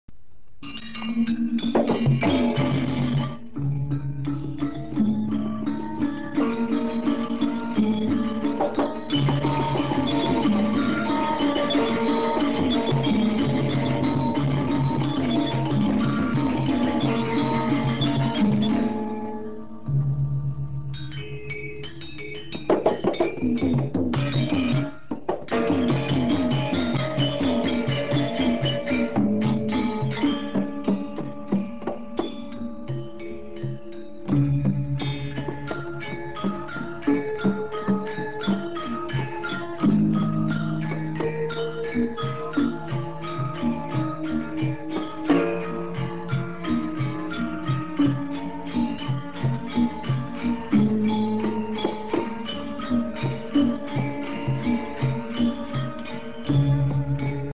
BaliMusic.mp3